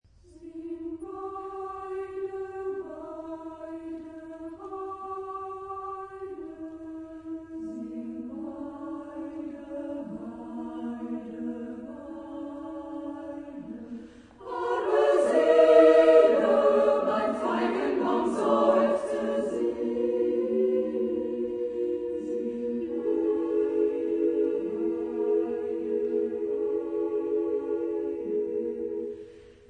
Genre-Style-Forme : Profane ; Lied
Type de choeur : SSAAA  (5 voix égales de femmes )
Réf. discographique : 3.Deutscher Chorwettbewerb, 1990